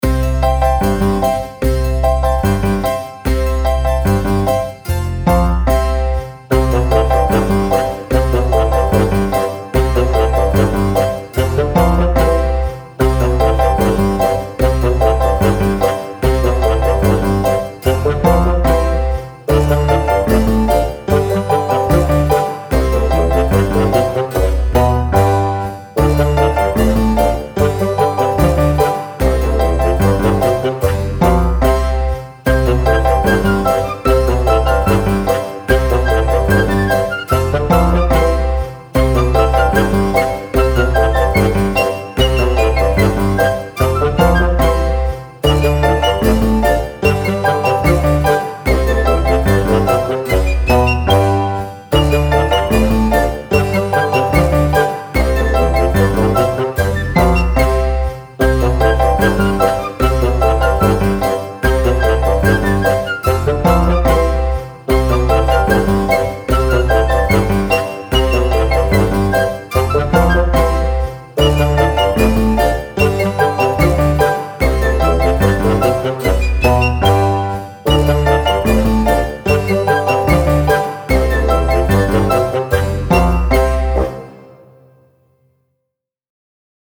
Style Style Oldies, Other
Mood Mood Bouncy, Bright, Funny +1 more
Featured Featured Bass, Flute, Harpsichord +2 more
BPM BPM 148